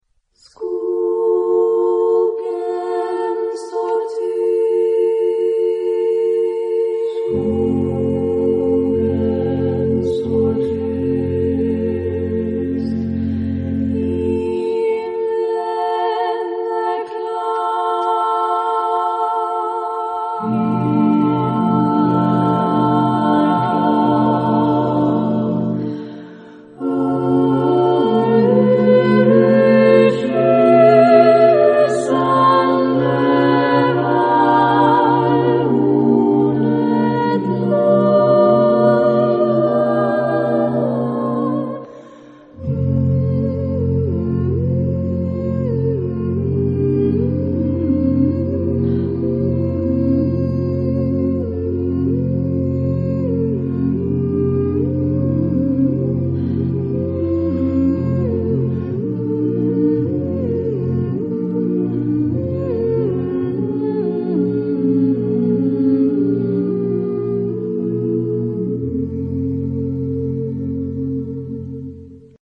SSATTBB (7 voices mixed) ; Full score.